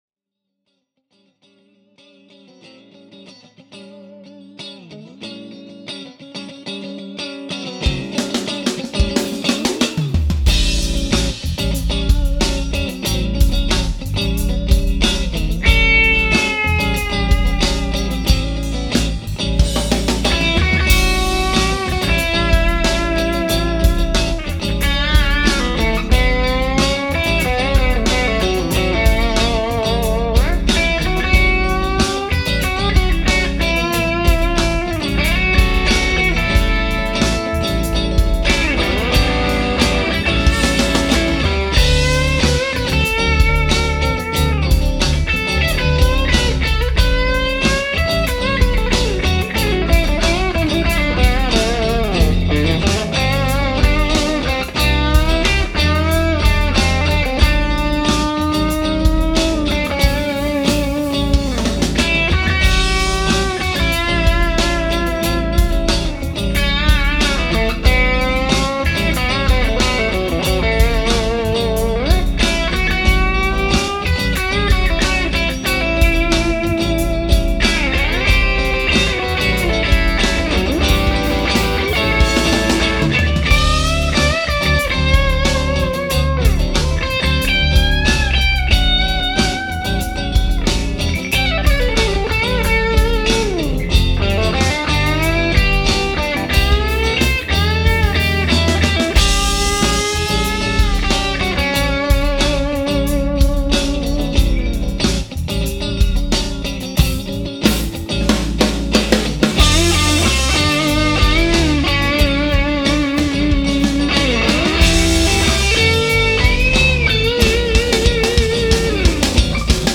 Finally, here’s my new song Strutter again with the Tele played in the bridge position through my Aracom VRX22:
But this guitar just sings and resonates all day long!
Guitars were recorded at conversation levels using the Aracom PRX150-Pro attenuator.
Effects: KASHA Overdrive, GeekMacDaddy Geek Driver